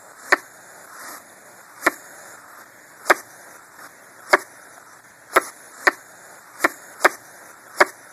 The "quacking" noise made by a hedgehog when annoyed. This vocalisation may be accompanied by bristling spines.
hedgehog_quack.mp3